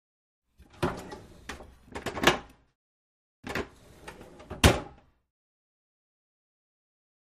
Broiler Door | Sneak On The Lot
Broiler Door; Open / Close; Broiler Door Open / Close. Close Perspective. Kitchen, Restaurant.